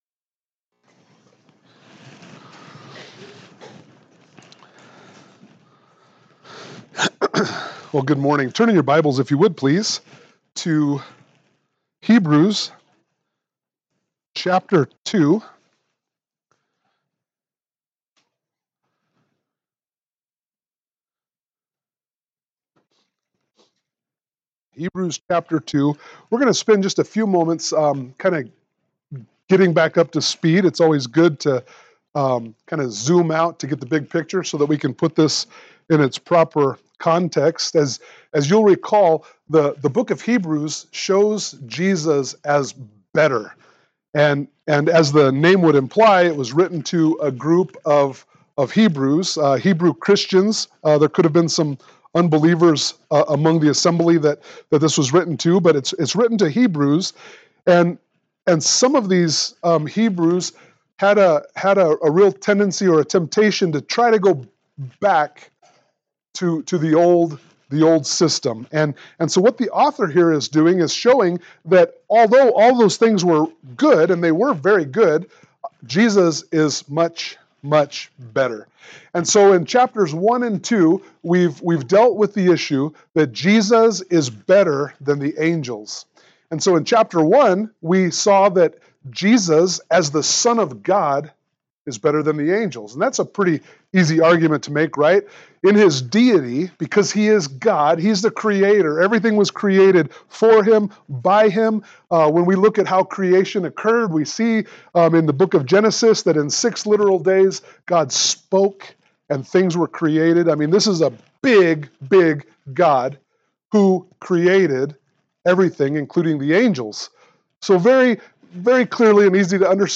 Hebrews 2:10-18 Service Type: Sunday Morning Worship « Hebrews 2:5-9 Hebrews Chapter 3